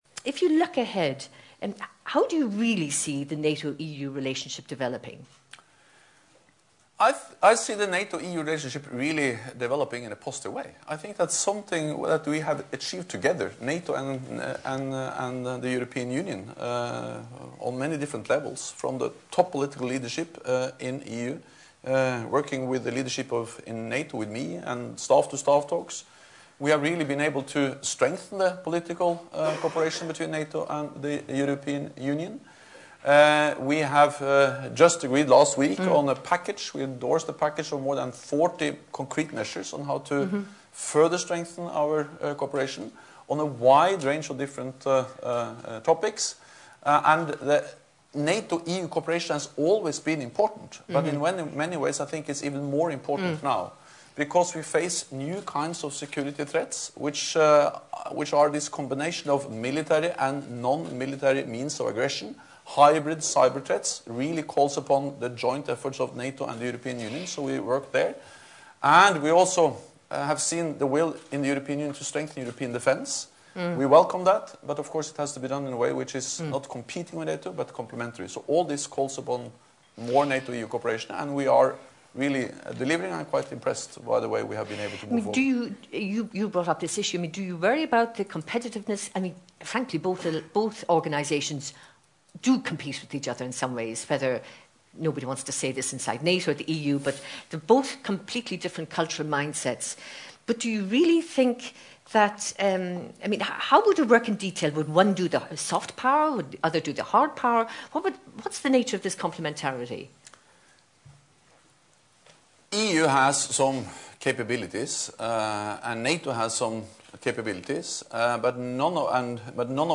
Keynote address
by NATO Secretary General Jens Stoltenberg to commemorate the 60th anniversary of the Three Wise Men Report, Norway House, Brussels